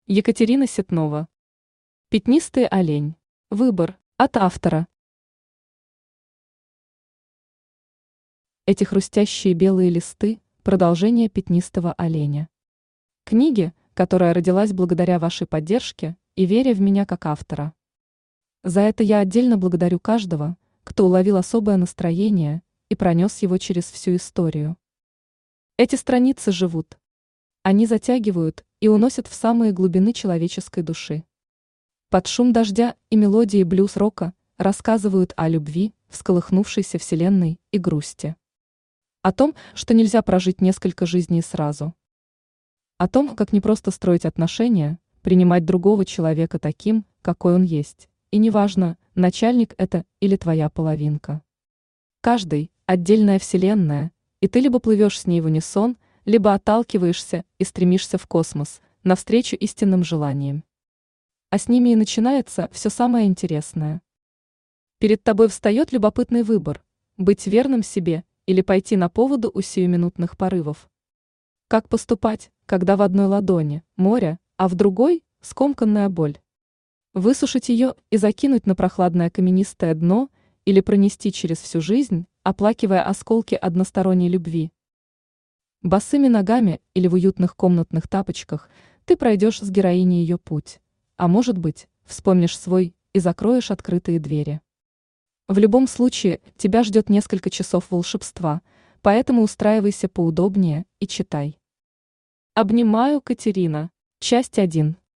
Аудиокнига Пятнистый олень. Выбор | Библиотека аудиокниг
Выбор Автор Екатерина Ситнова Читает аудиокнигу Авточтец ЛитРес.